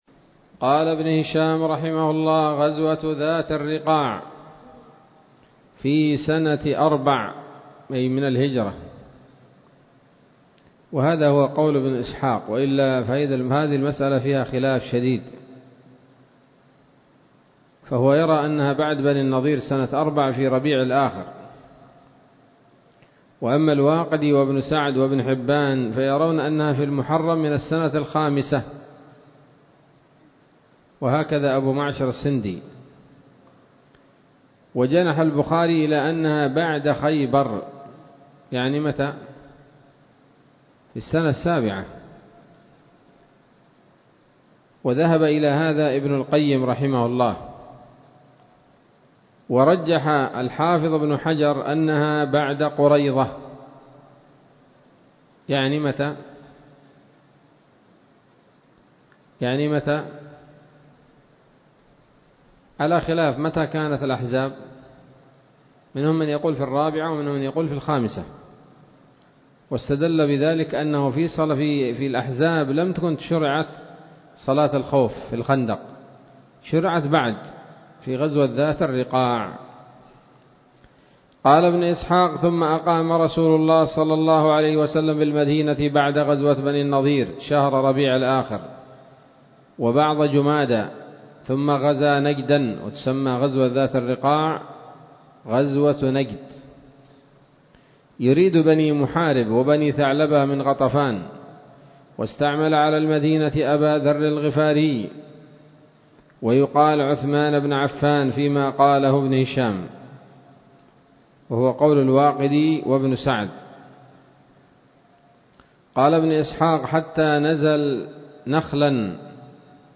الدرس الثاني والتسعون بعد المائة من التعليق على كتاب السيرة النبوية لابن هشام